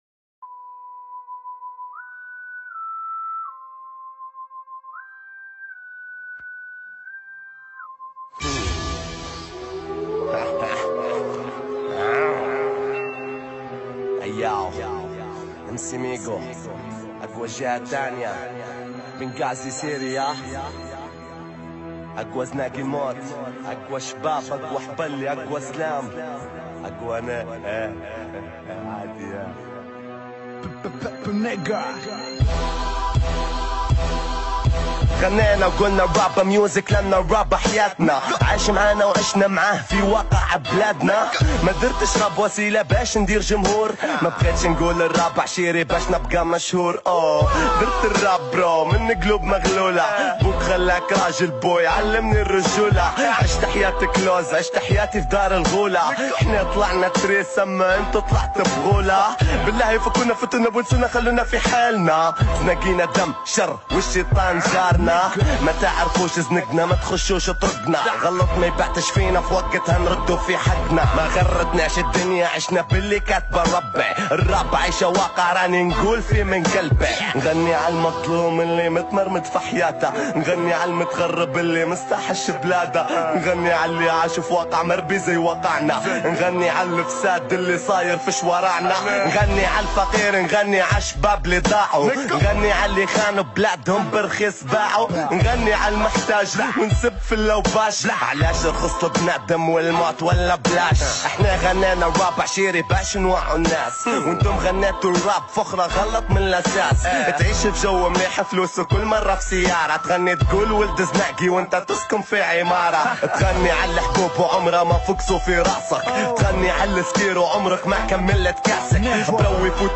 الراب